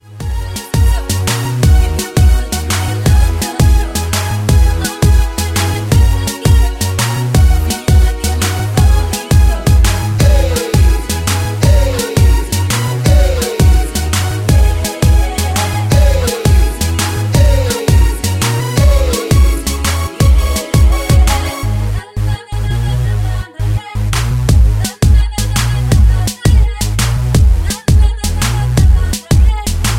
Backing track files: 2010s (1044)
Buy With Backing Vocals.